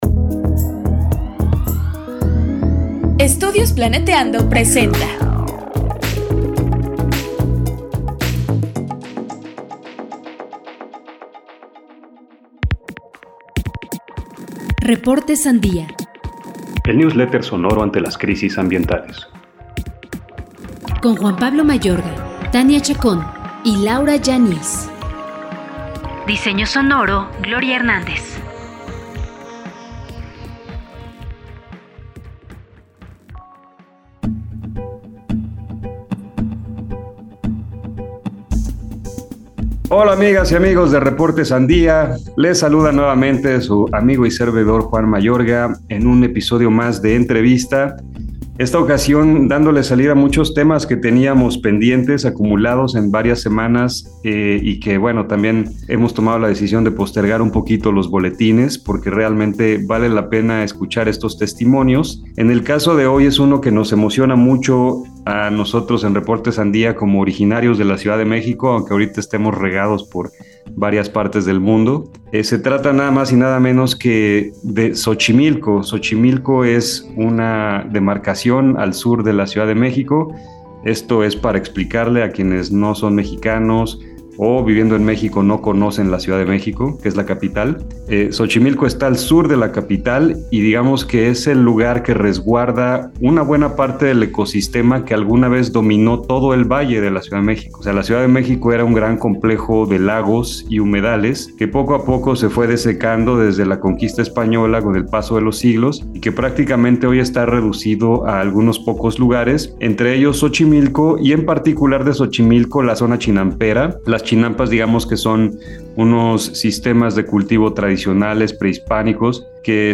Los pueblos originarios de Xochimilco defienden su territorio. Entrevista.